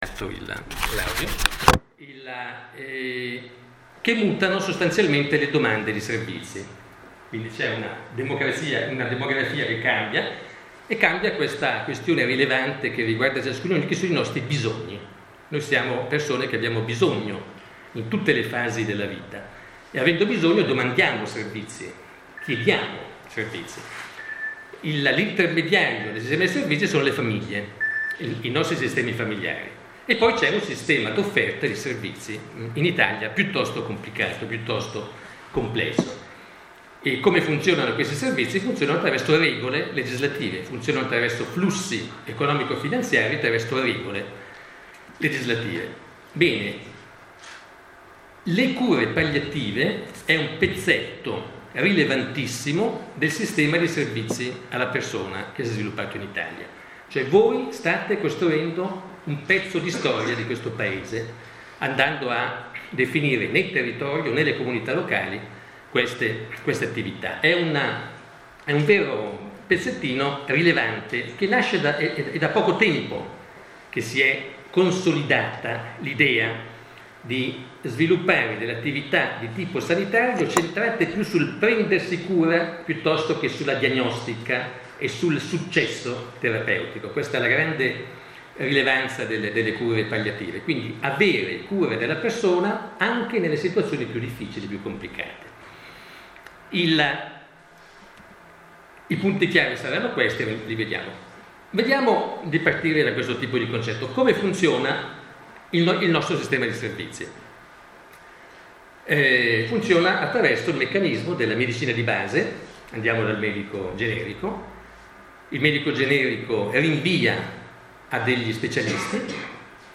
LE CURE PALLIATIVE E IL SISTEMA DEI SERVIZI: ASPETTI SOCIODEMOGRAFICI E LEGISLAZIONE. Relazione all’interno del percorso formativo: la SOFFERENZA NELLA MALATTIA, a cura dell’Associazione A.MaTe onlus e l’R.S.A. San Carlo, Borromeo Fatebenefratelli di Solbiate, 16 febbraio 2017.